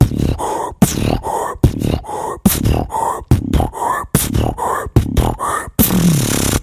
Звуки битбокса
Дубстеп в битбоксе